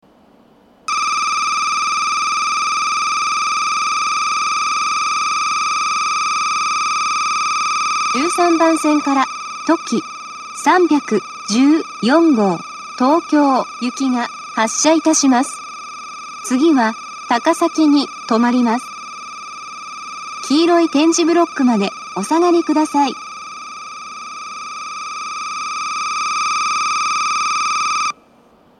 ２０２１年１０月１日にはCOSMOS連動の放送が更新され、HOYA製の合成音声による放送になっています。
１３番線発車ベル とき３１４号東京行の放送です。